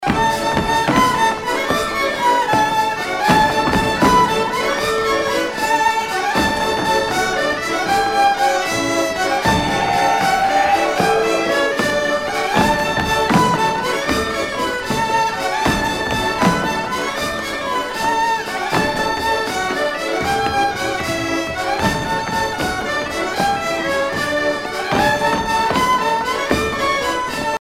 danse : bourree
Pièce musicale éditée